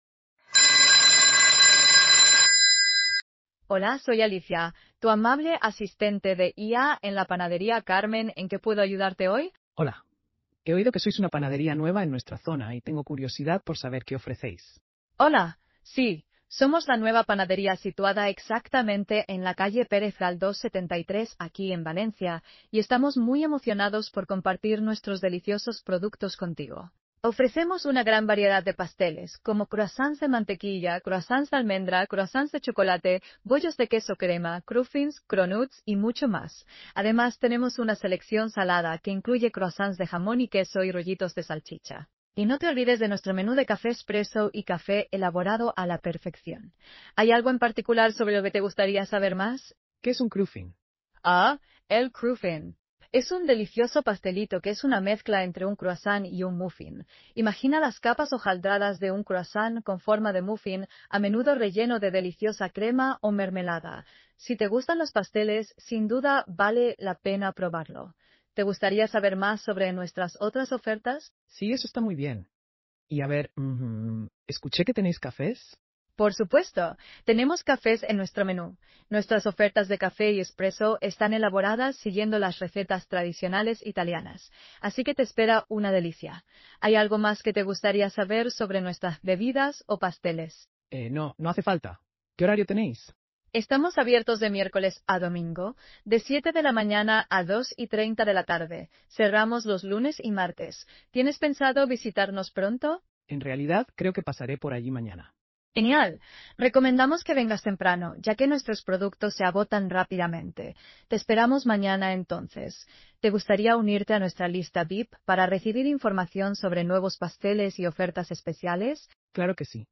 Escuche a continuación una demostración de voz AI personalizada
Recepcionista de Inteligencia Artificial